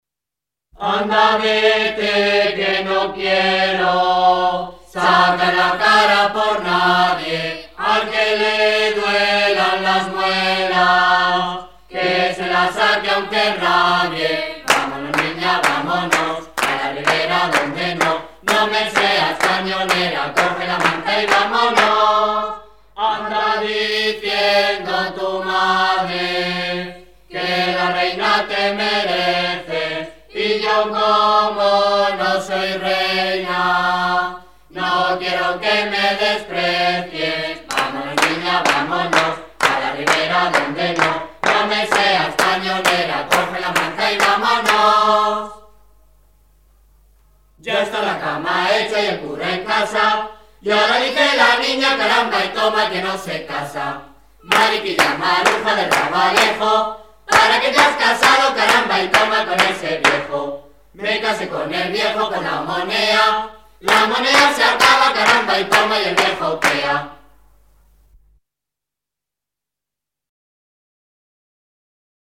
Melenchones_de_Jaén.mp3